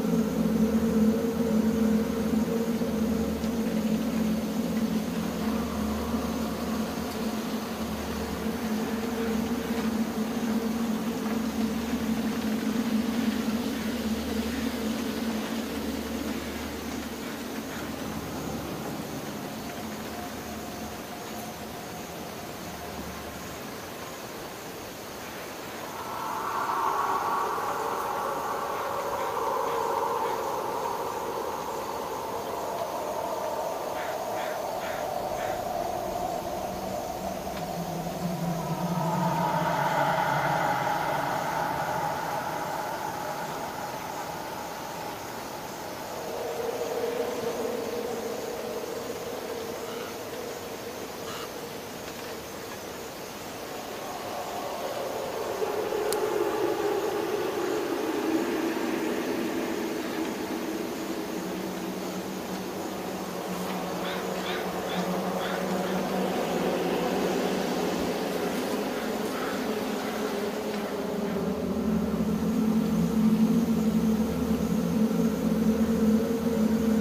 白噪声书店外.ogg